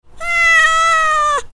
دانلود آهنگ گربه برای اس ام اس از افکت صوتی انسان و موجودات زنده
دانلود صدای گربه برای اس ام اس از ساعد نیوز با لینک مستقیم و کیفیت بالا
جلوه های صوتی